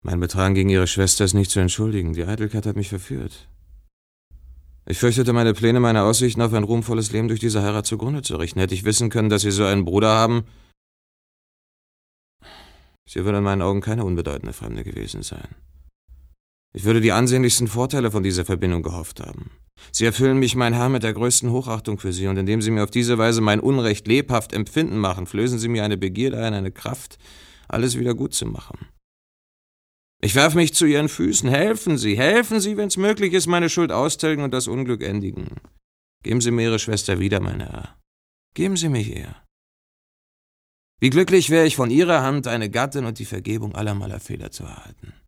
plakativ
Mittel plus (35-65)
Lip-Sync (Synchron)